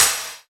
Percussion #12.wav